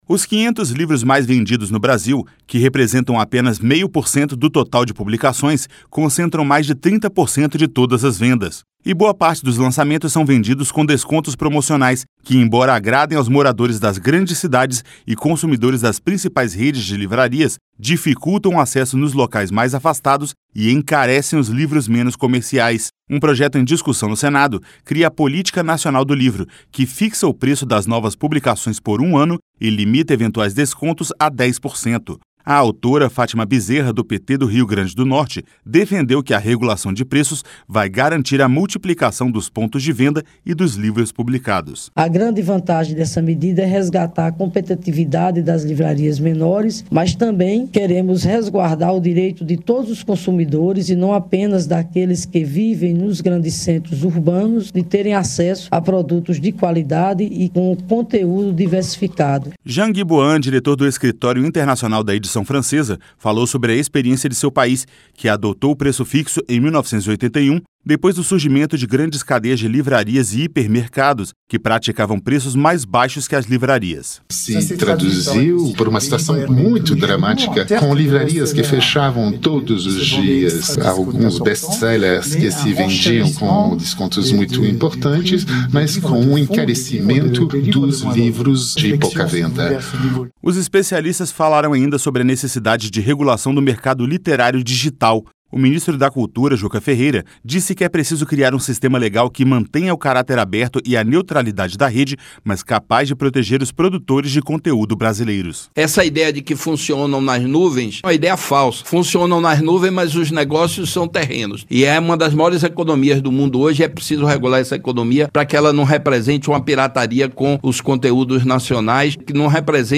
Rádio Senado